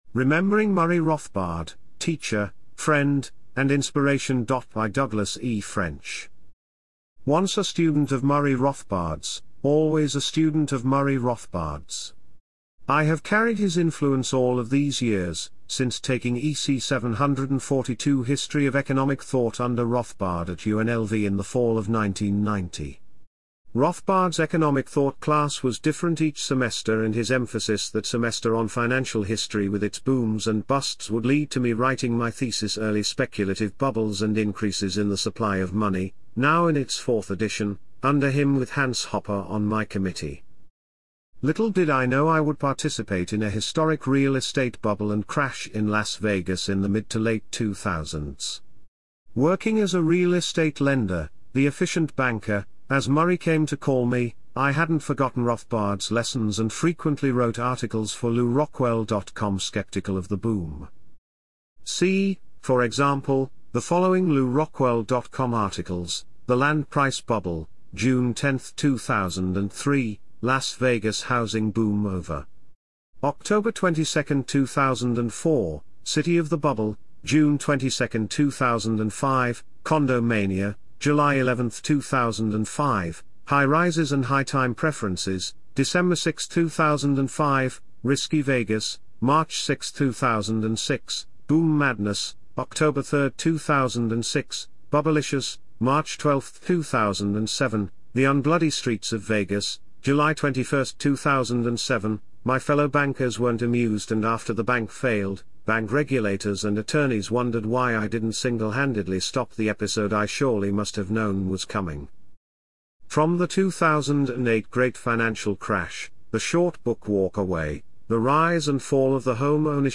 AI-assisted audio narration of the main chapters of Rothbard at 100: A Tribute and Assessment (Papinian Press and The Saif House, 2026) is available at this PFS Youtube Playlist; the mp3 files may also be downloaded in this zip file.